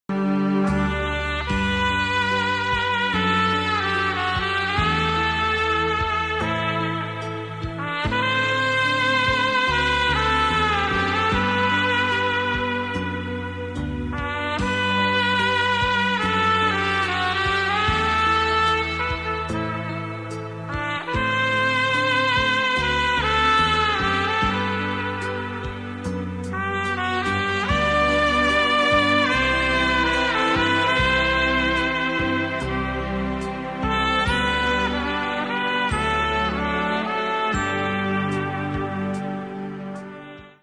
ترومپت (۱)
ترمپت